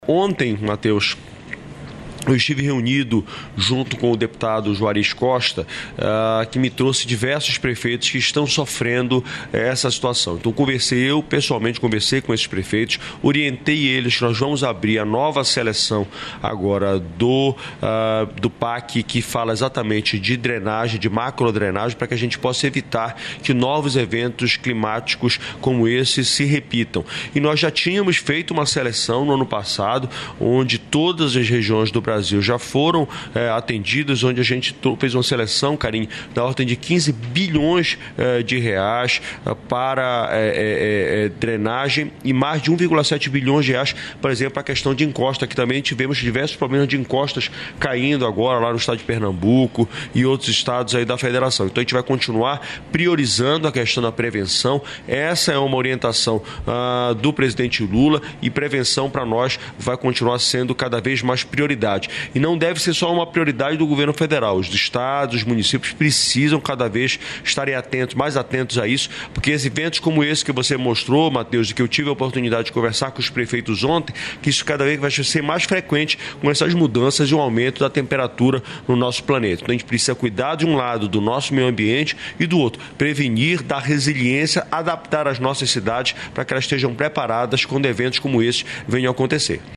Trecho da participação do ministro das Cidades, Jader Filho, no programa "Bom Dia, Ministro" desta quarta-feira (12), nos estúdios da EBC no Encontro de Novos Prefeitos e Prefeitas, em Brasília.